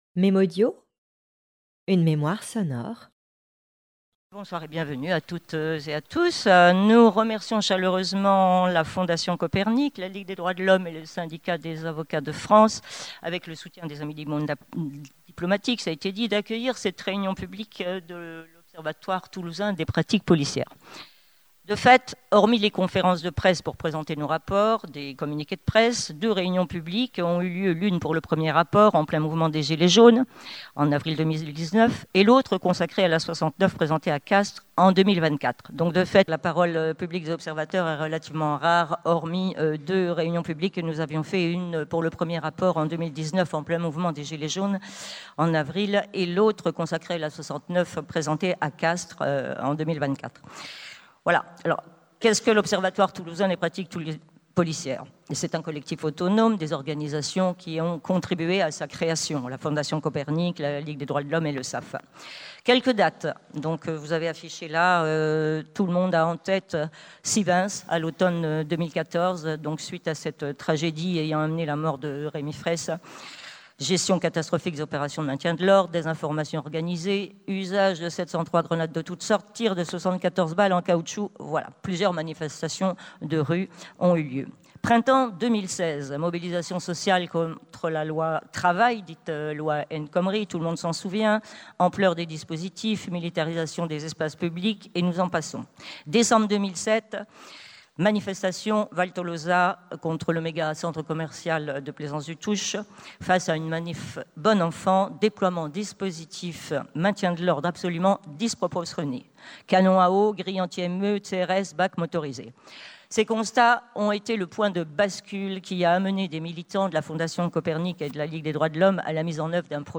Table ronde autour des violences policières, Salle du Sénéchal à Toulouse, le 8 octobre 2025, avec la LDH, fondation Copernic, l'OPP et le SAS.
Table ronde